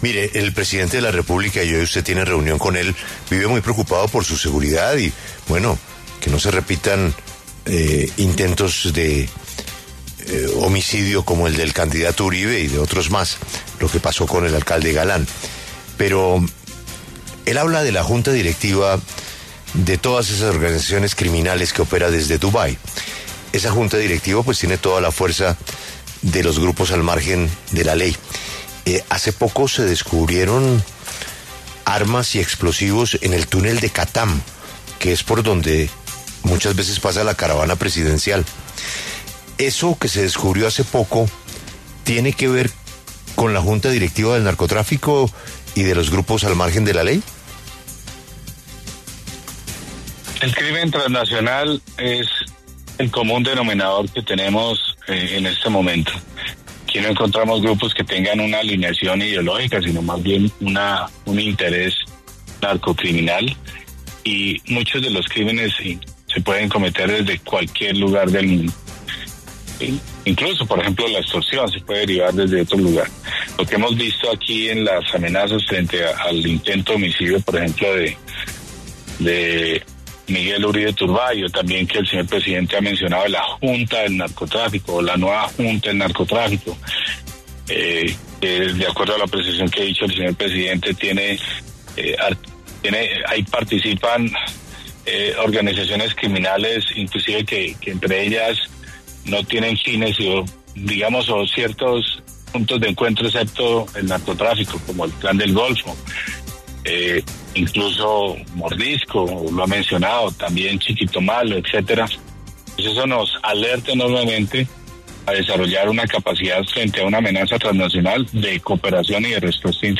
El ministro de defensa, Pedro Sánchez, reveló en primicia en W Radio que en Santa Marta, debajo de un puente, encontraron un armamento.